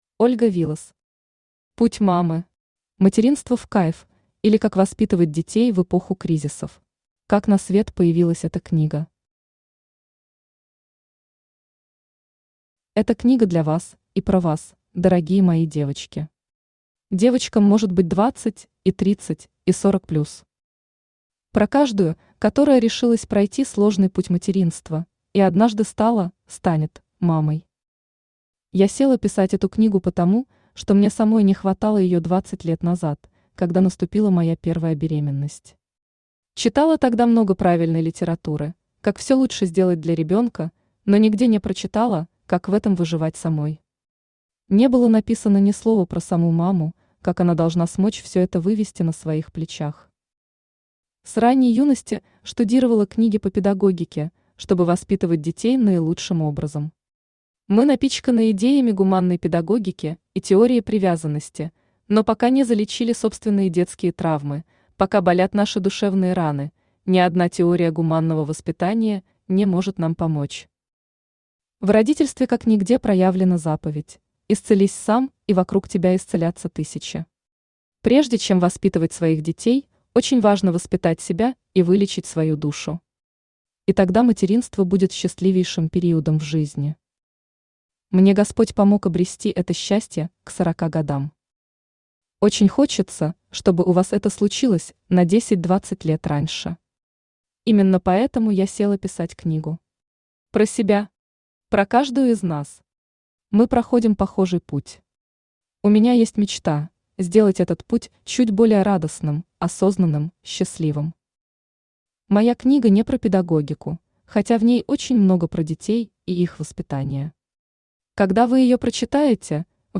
Материнство в кайф, или Как воспитывать детей в эпоху кризисов Автор Ольга Вилас Читает аудиокнигу Авточтец ЛитРес.